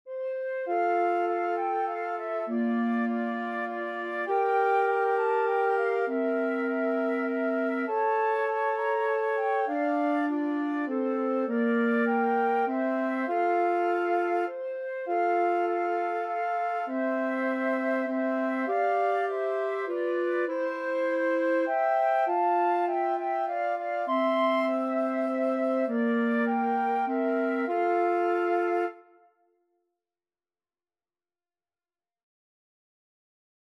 Flute 1Flute 2Clarinet
Moderato
3/4 (View more 3/4 Music)